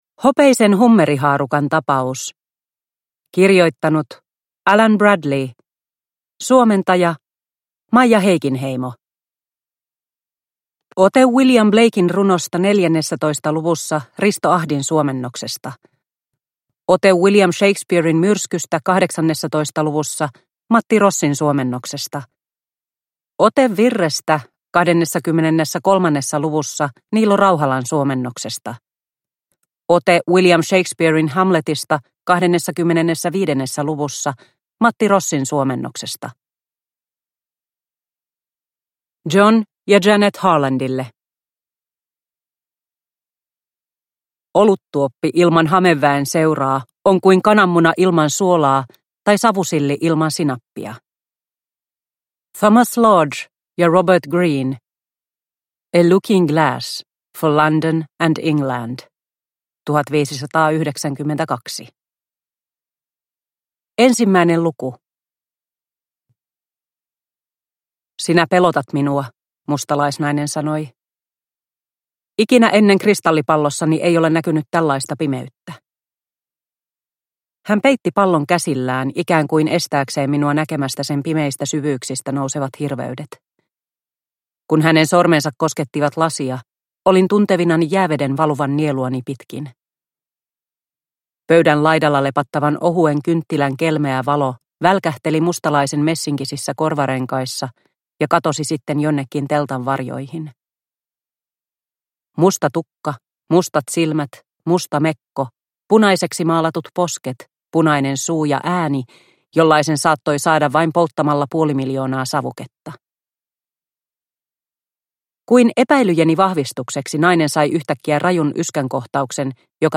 Hopeisen hummerihaarukan tapaus – Ljudbok – Laddas ner